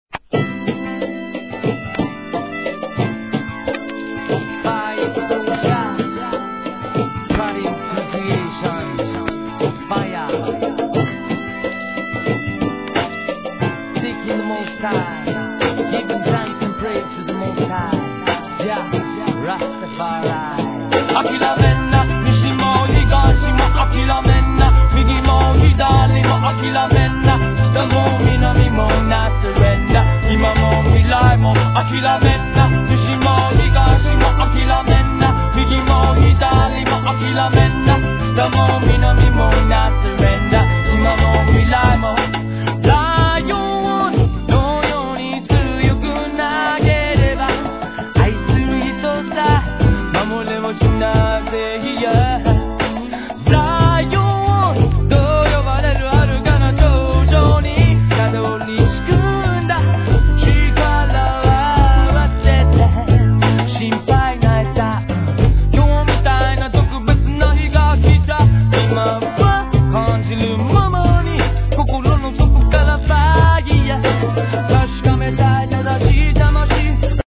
JAPANESE REGGAE